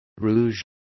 Complete with pronunciation of the translation of rouge.